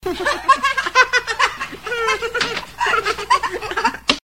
children-laughing.mp3
Original creative-commons licensed sounds for DJ's and music producers, recorded with high quality studio microphones.
children-laughing_mcw.ogg